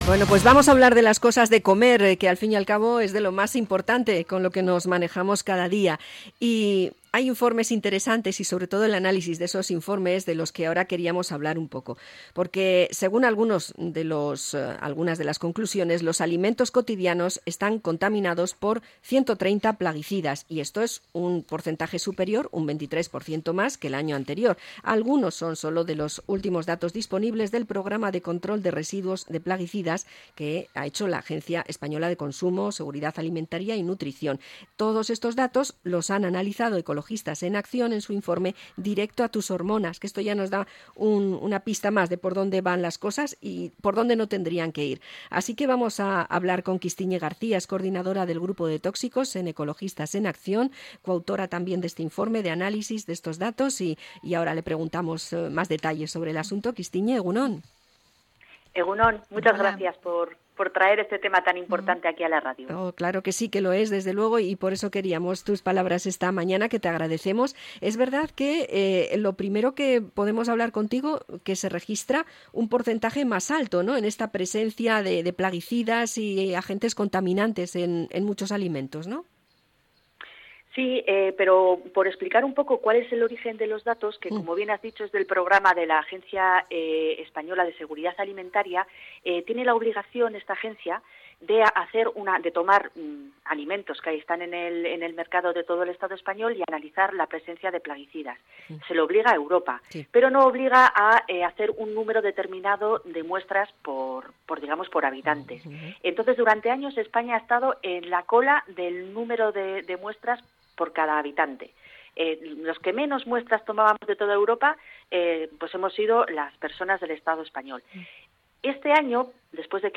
Entrevista a Ekologistak Martxan por los plaguicidas de las frutas